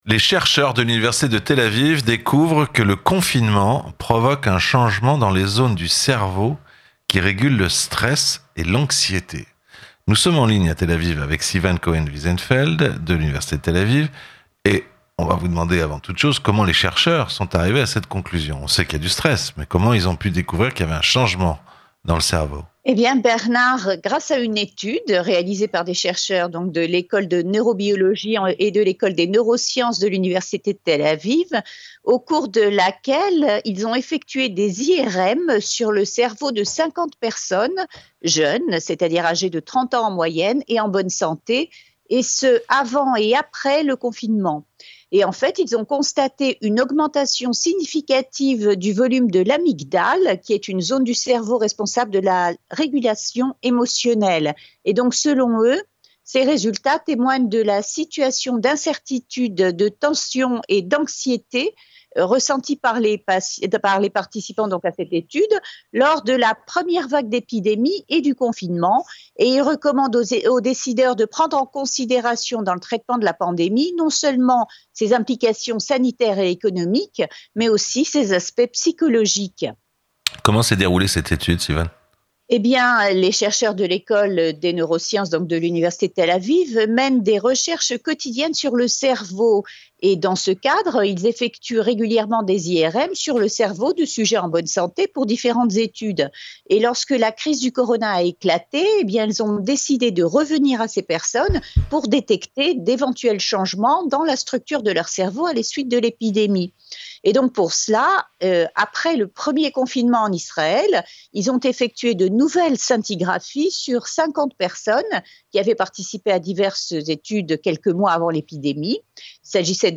RADIO SHALOM EN DIRECT